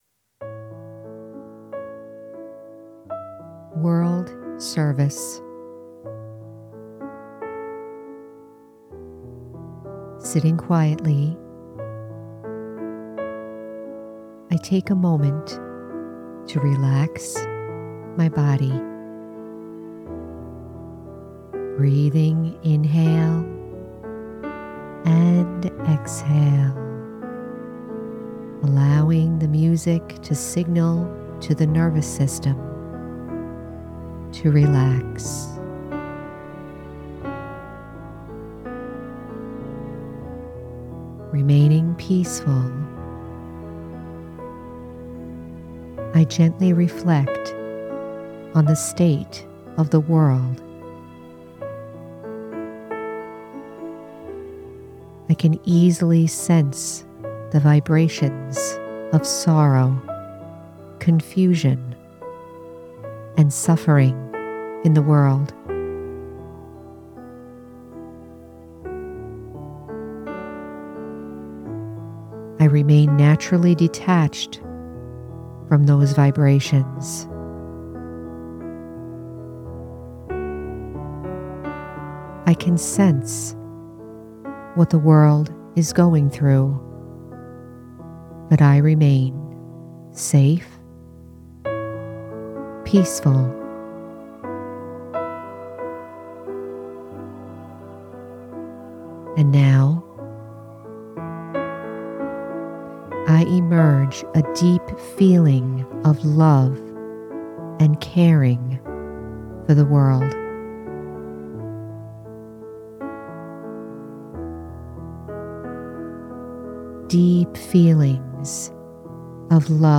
World Service- Guided Meditation- The Spiritual American- Episode 169